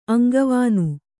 ♪ aŋgavānu